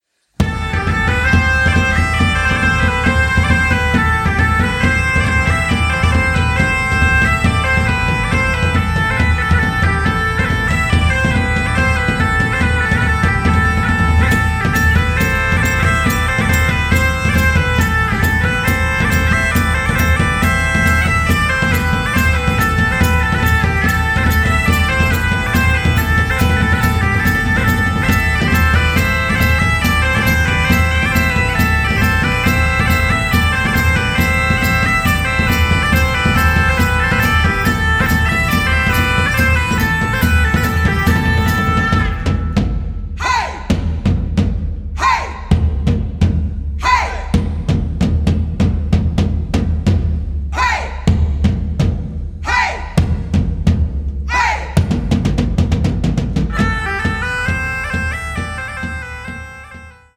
12 Songs aus traditionellem Liedgut